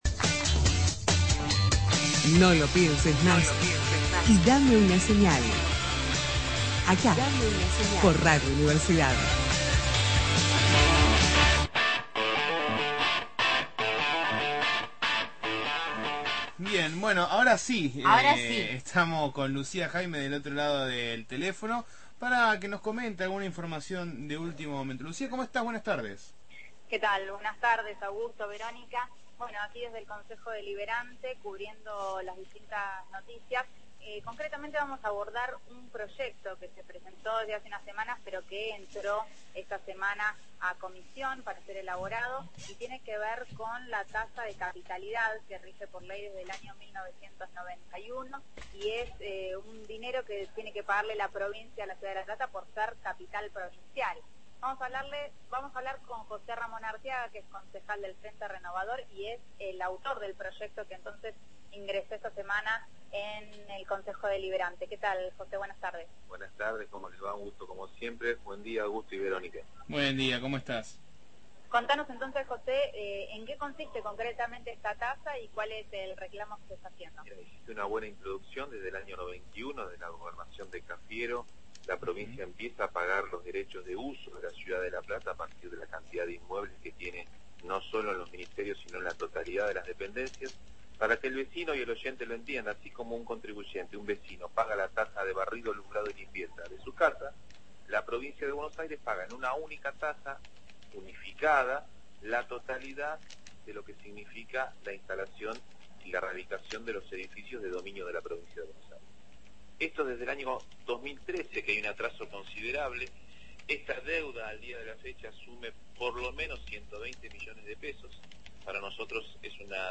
MÓVIL/ Concejal de La Plata, José Arteaga sobre la tasa de capitalidad – Radio Universidad